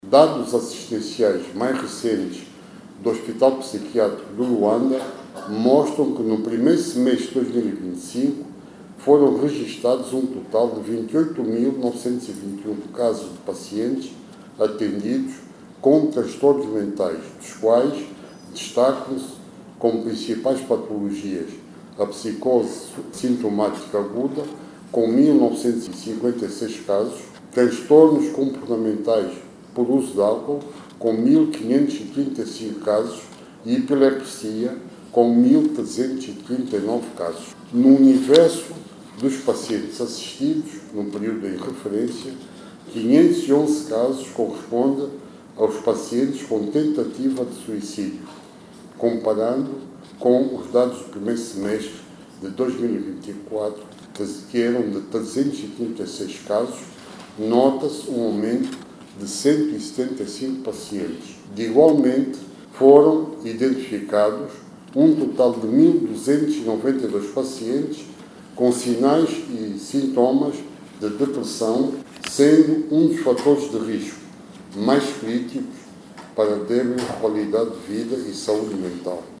O governante falava esta sexta-feira, na abertura das décimas Jornadas Científicas do Hospital Psiquiátrico de Luanda.